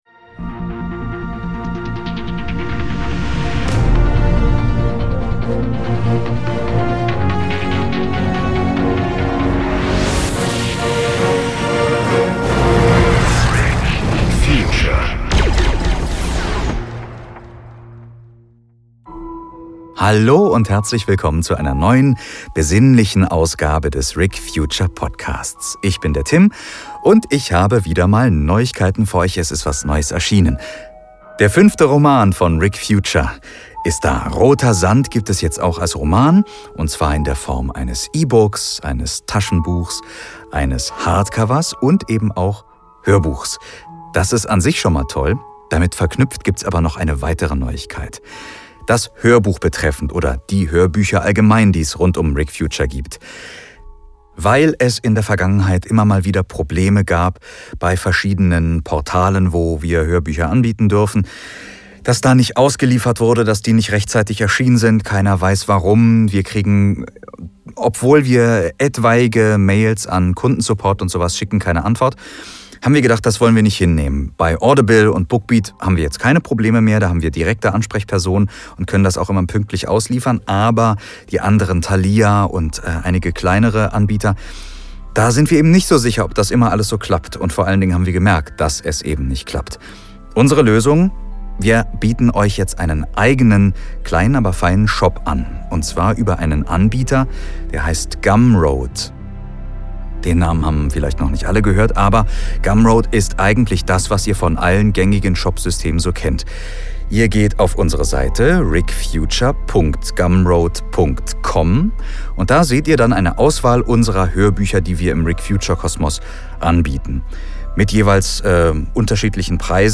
Obendrauf gibt es ein ganzes Kapitel als Hörprobe aus dem neuen Buch.